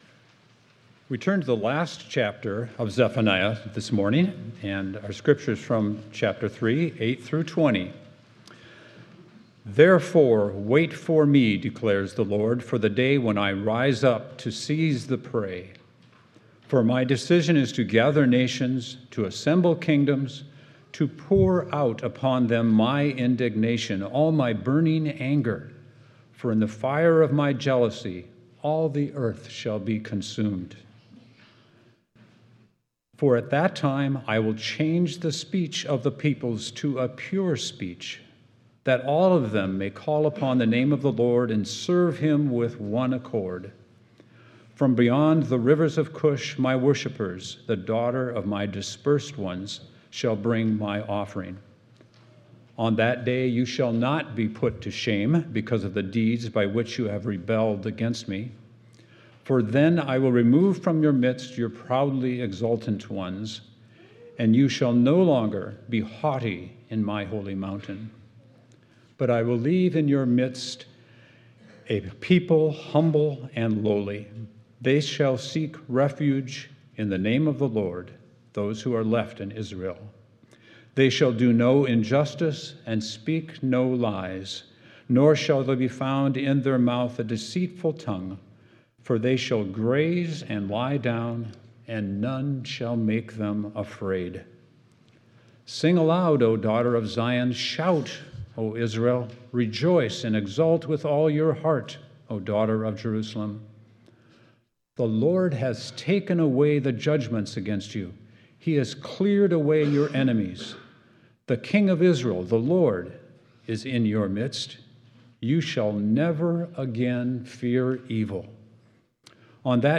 5.11.25 sermon.m4a